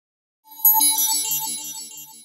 Category: HTC Ringtones